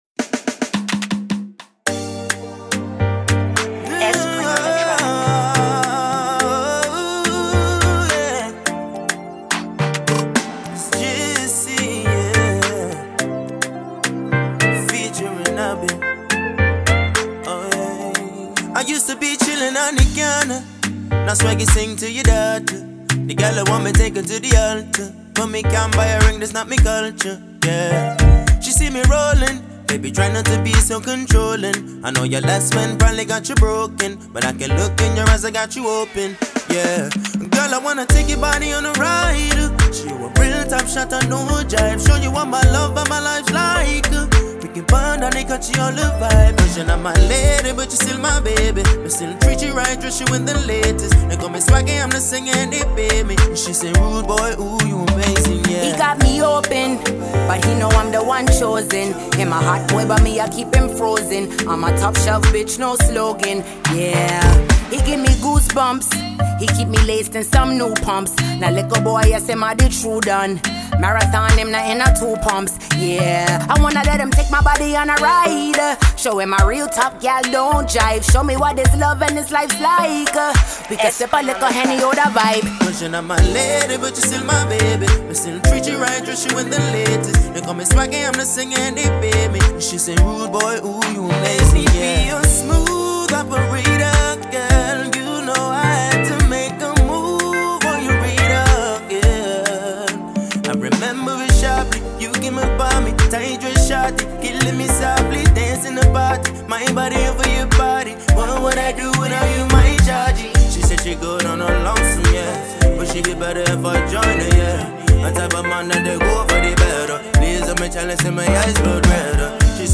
Reggae
dope reggae/Afro Beat influenced joint